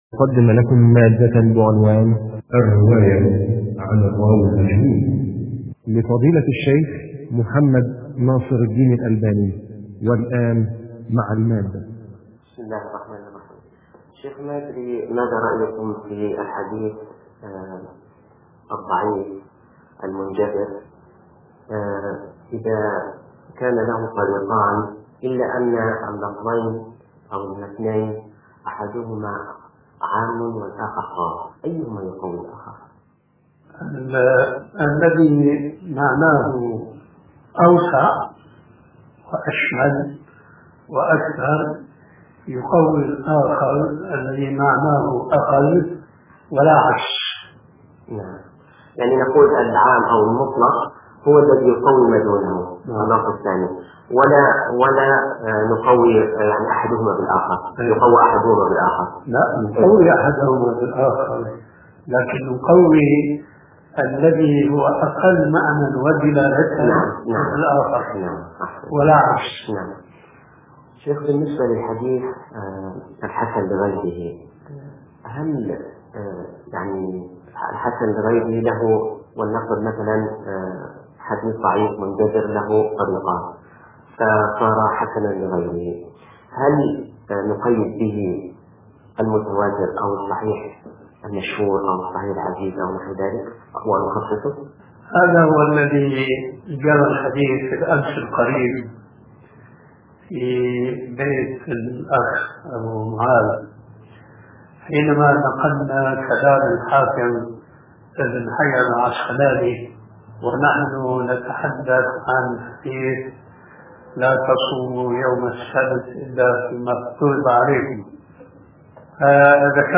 شبكة المعرفة الإسلامية | الدروس | الرواية عن المجهول |محمد ناصر الدين الالباني